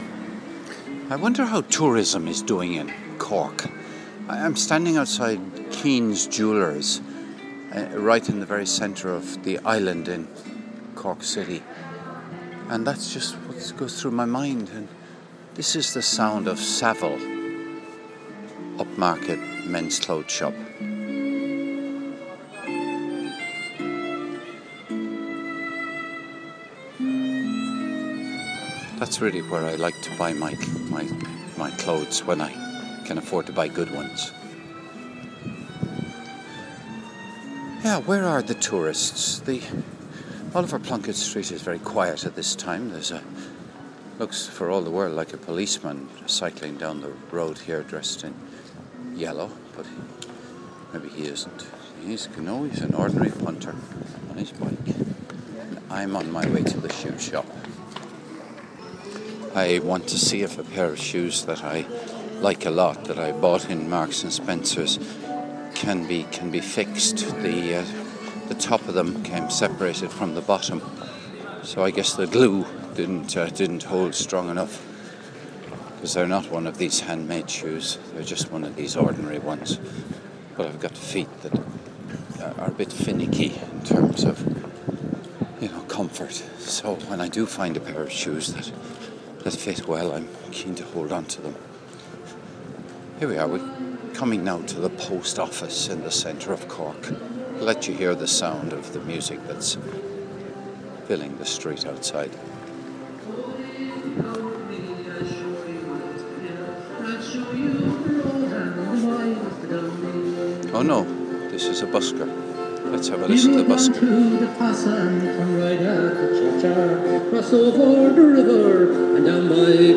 A cobbler's story Season 1, Episode 1644, Aug 11, 2012, 11:05 AM Facebook Twitter Headliner Embed Embed Code See more options Saturday morning in downtown Cork 11 August 2012 A busker plays & sings... Most of this audio is about a pair of shoes from M&S